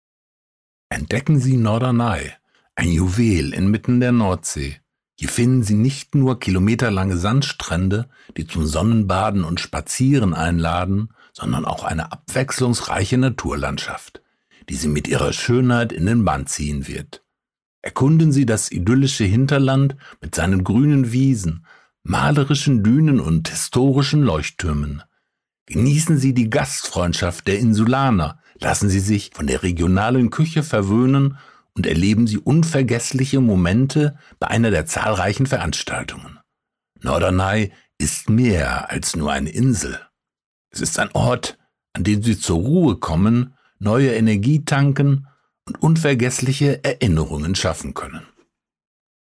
Fictitious ad for a German isle in the North Sea - German